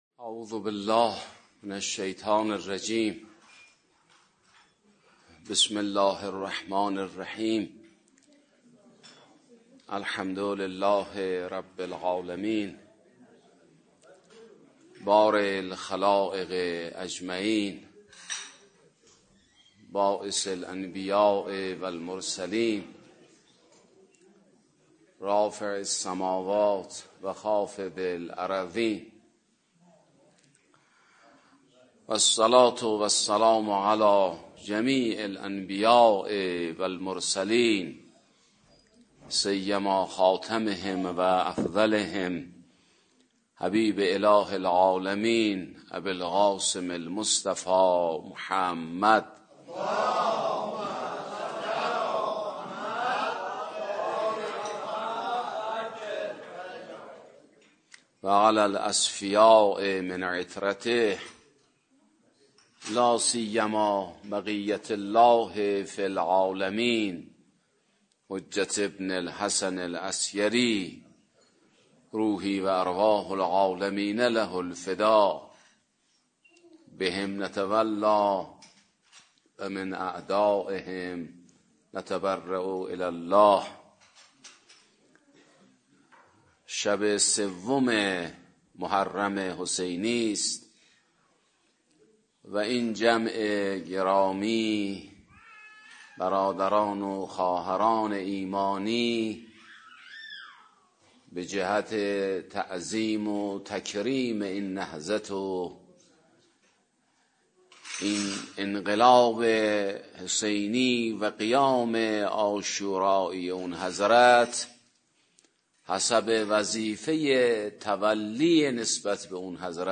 سومین شب از مراسم عزاداری حضرت اباعبدالله الحسین(ع)، با حضور مؤمنان و دلدادگان اهل بیت عصمت و طهارت برگزار شد.